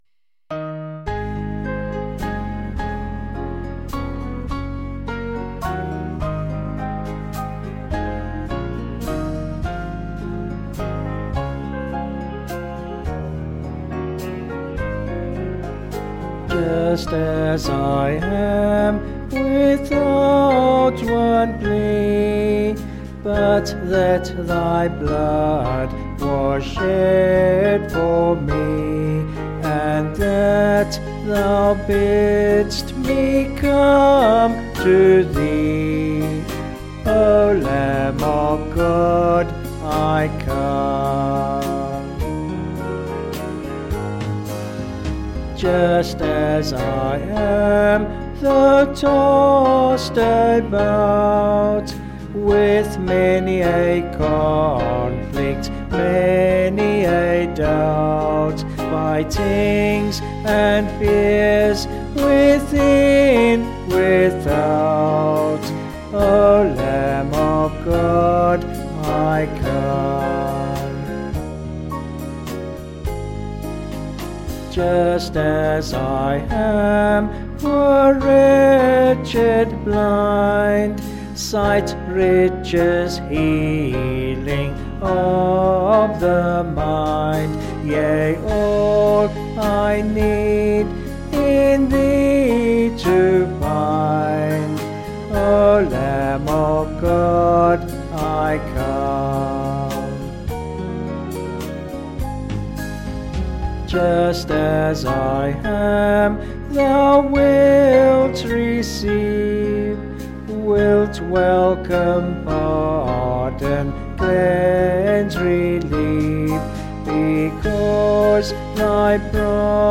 (BH)   6/Db-D
Vocals and Band   265kb Sung Lyrics